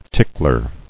[tick·ler]